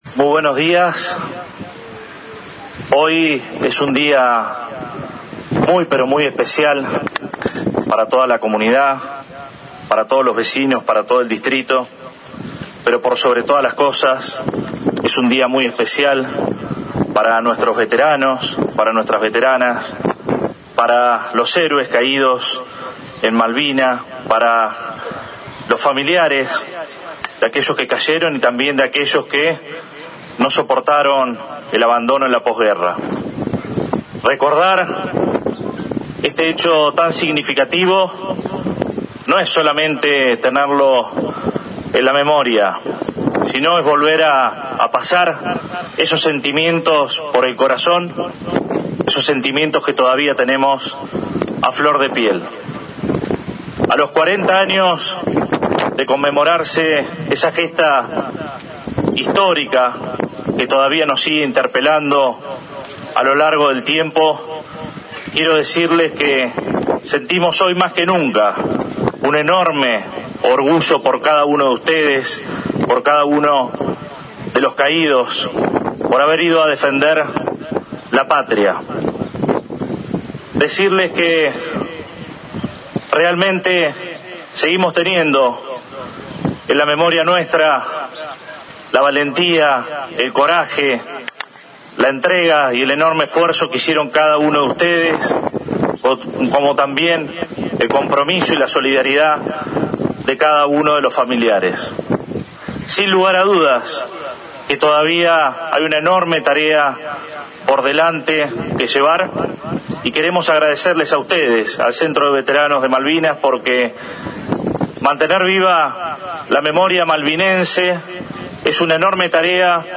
Junto al Centro de Veteranos local, encabezó el acto el intendente municipal, Arturo Rojas, quien al hacer uso de la palabra afirmó que “recordar este hecho tan significativo es volver a pasar esos sentimientos por el corazón que todavía tenemos a flor de piel”, y dirigiéndose directamente a los excombatientes expresó: “A 40 años de conmemorarse una gesta histórica que todavía nos sigue interpelando, queremos decirles que sentimos hoy más que nunca un enorme orgullo por cada uno de ustedes”.
Audio Intendente Arturo Rojas.